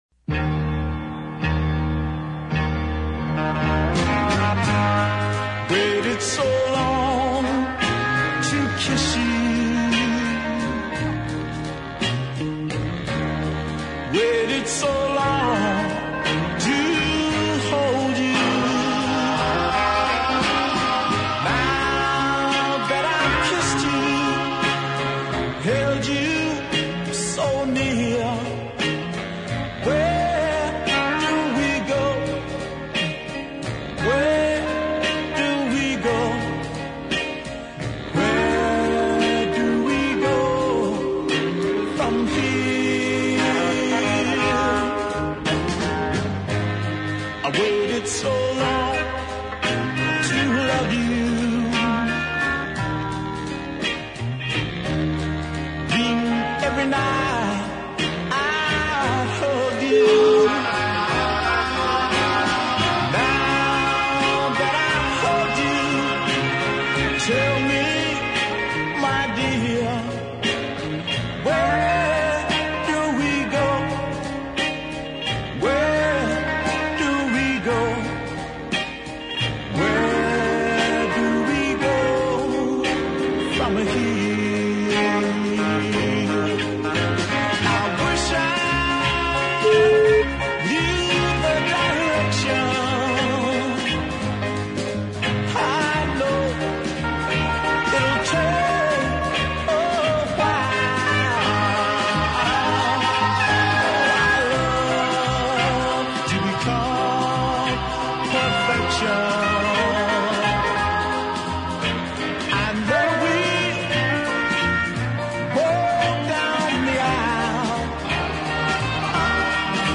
big city ballad of great power and with a fine melody
and a tasteful male chorus.